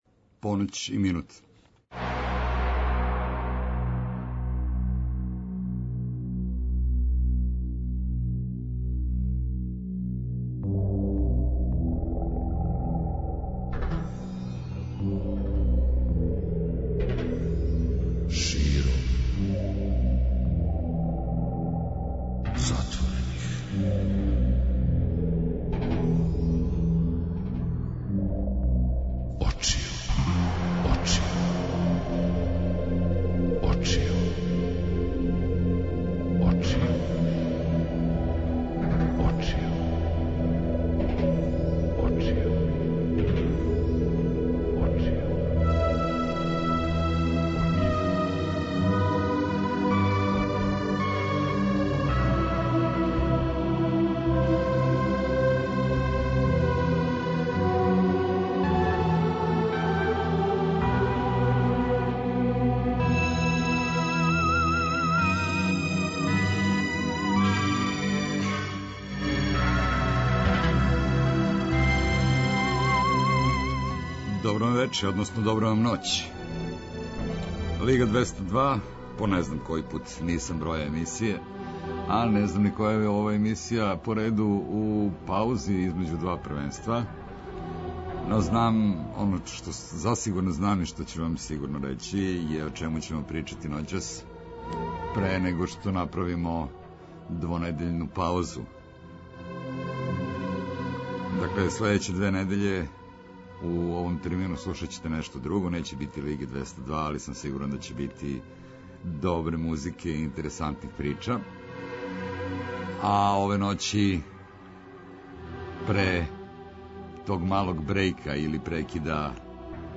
У ноћном програму Широм затворених очију, а у оквиру Лиге 202, у паузи између две сезоне, причамо о интересантним књигама везаним за музику, а објављеним код нас.